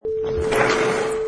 Sliding door opens
Product Info: 48k 24bit Stereo
Category: Doors / Sliding Doors
Relevant for: sliding, doors, slides, porch, opens, buildings, close, shut, metallic, loud, bang, locker, room, small.
Try preview above (pink tone added for copyright).
Tags: metal
Sliding_Door_Opens_4.mp3